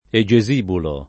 vai all'elenco alfabetico delle voci ingrandisci il carattere 100% rimpicciolisci il carattere stampa invia tramite posta elettronica codividi su Facebook Egesibulo [ e J e @ ib 2 lo ; alla greca e J e @& bulo ] pers. m. stor.